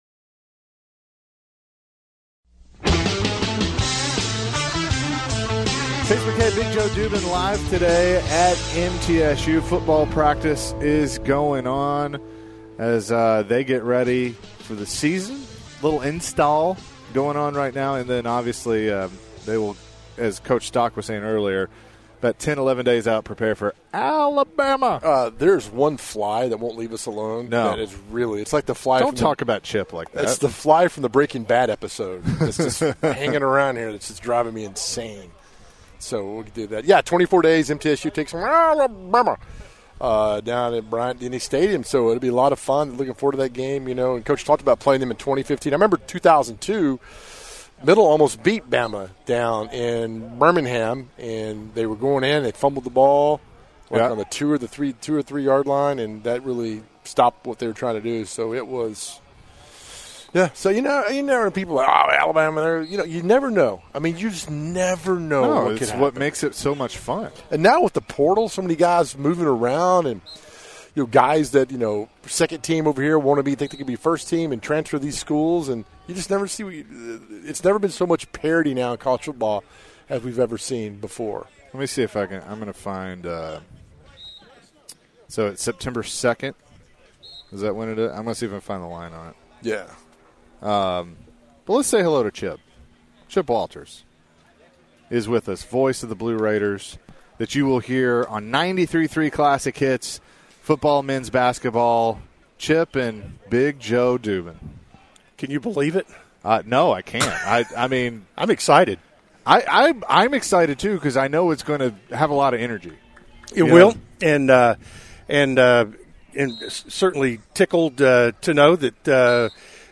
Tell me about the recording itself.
live at MTSU football practice